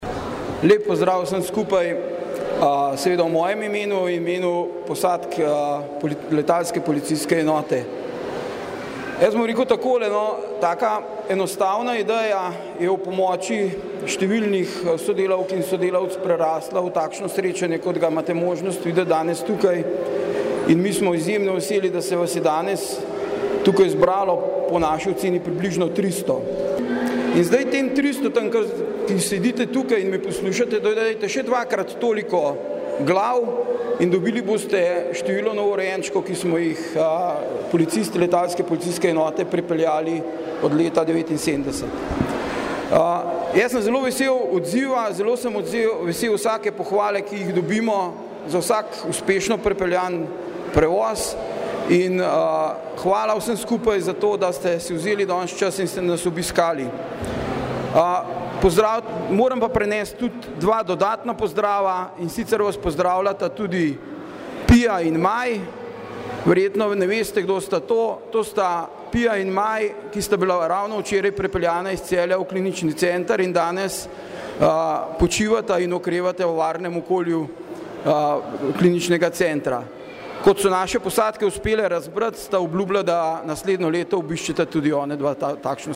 V hangarju Letalske policijske enote je bilo več kot dovolj prostora za dobro voljo in veselje.